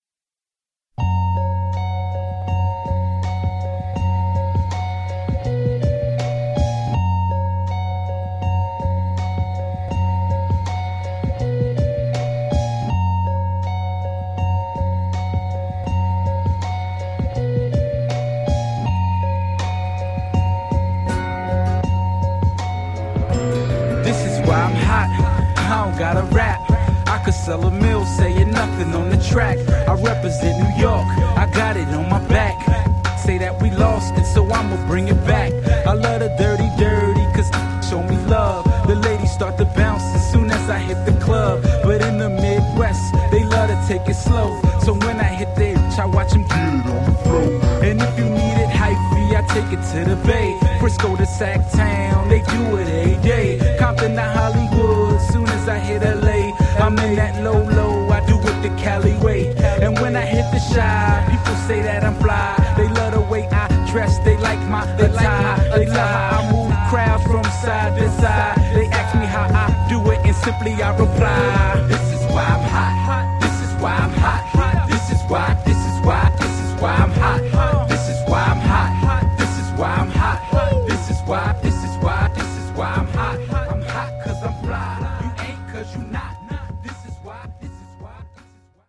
81 bpm
Clean Version